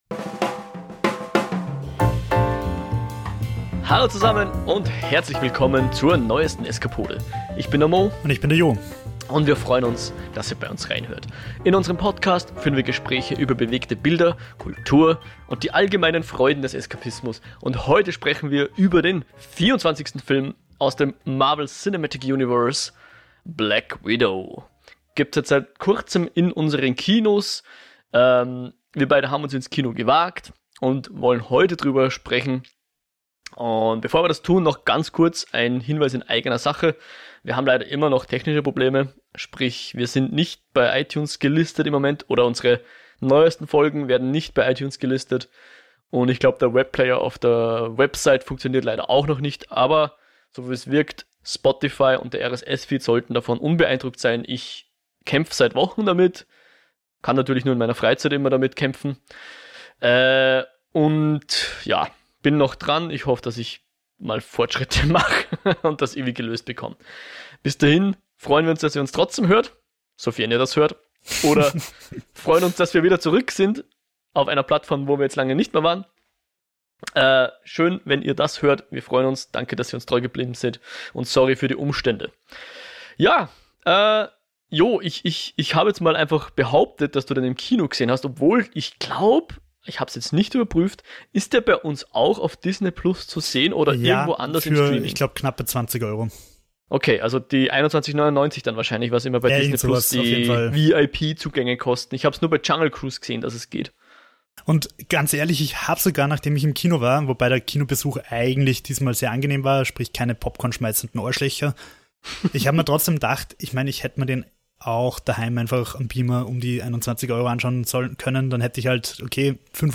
Gespräche über bewegte Bilder, Kultur und die allgemeinen Freuden des Eskapismus